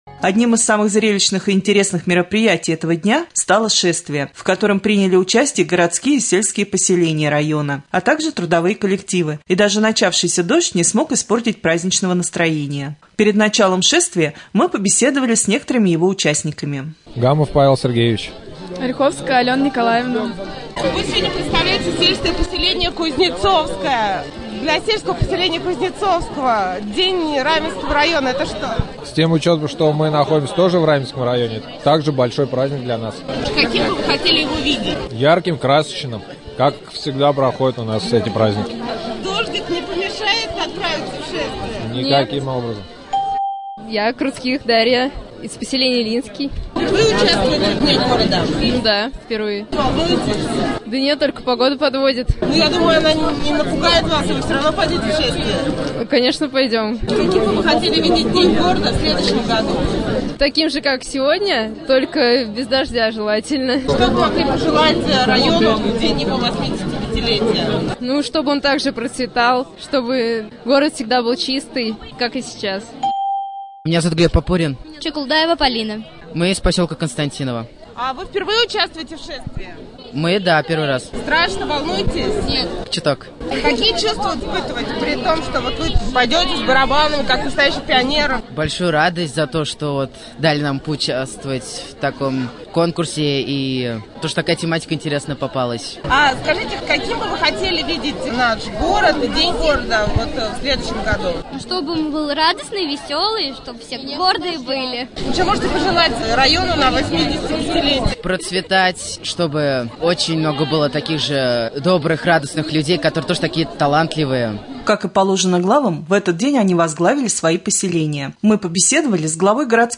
2.Рубрика «Специальный репортаж».12 июня мы побеседовали с участниками шествия, посвященного Дню района.